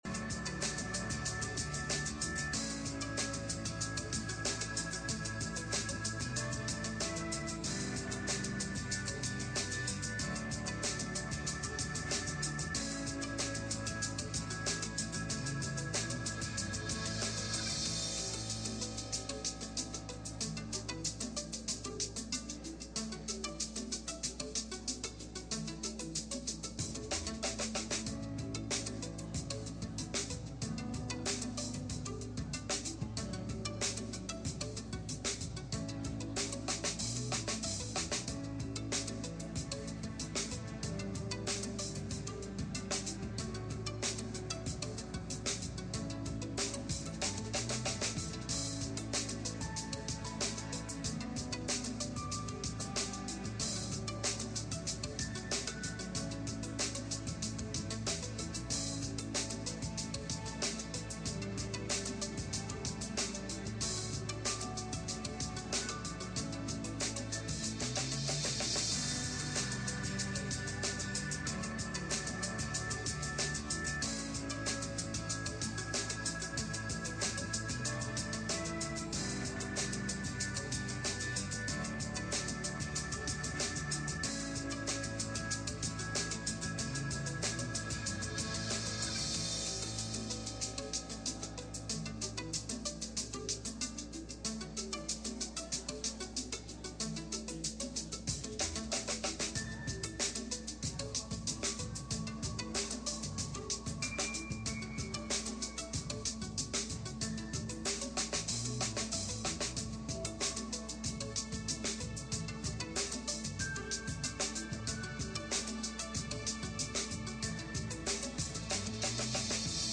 Luke 8:40-56 Service Type: Sunday Morning « The Four Anchors That Hold